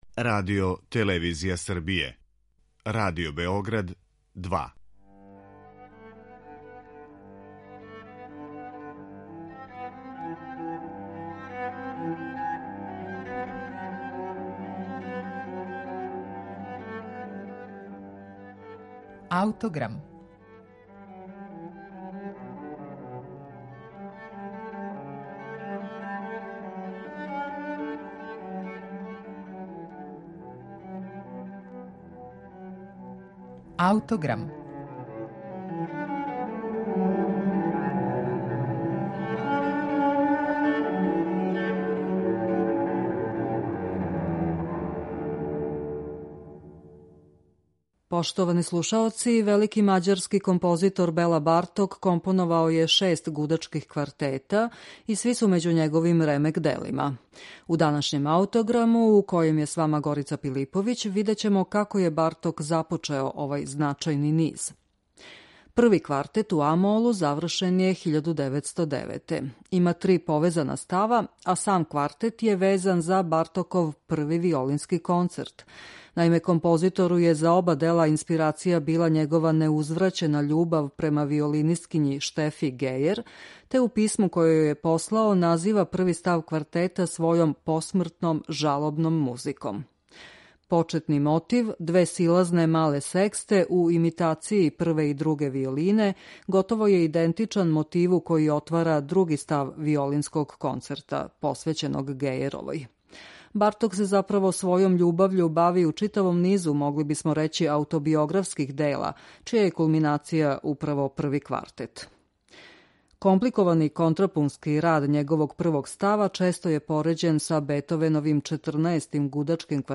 Први гудачки квартет Беле Бартока